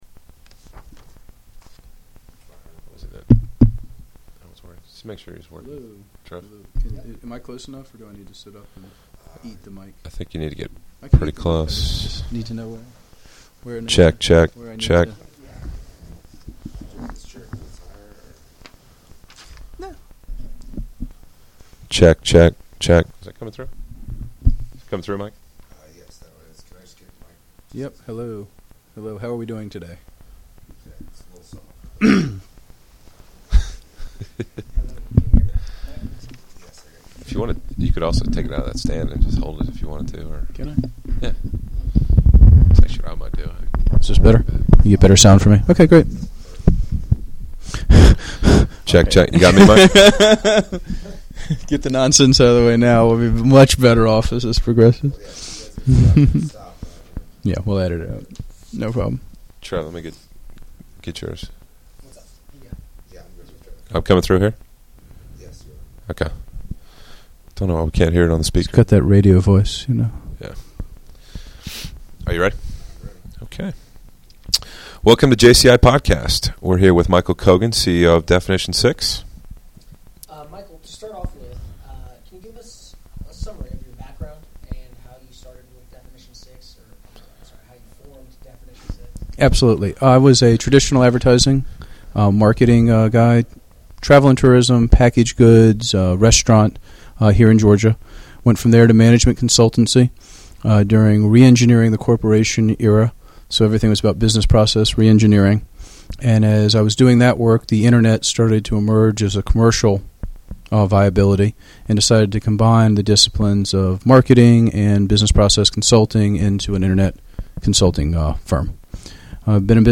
This podcast features speeches from many of Atlanta's top executives and entrepreneurs.